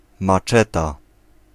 Ääntäminen
Synonyymit bolo Ääntäminen Tuntematon aksentti: IPA : /məˈʃɛ.ti/ Haettu sana löytyi näillä lähdekielillä: englanti Käännös Ääninäyte Substantiivit 1. maczeta {f} Määritelmät Substantiivit A sword -like tool used for cutting large plants with a chopping motion.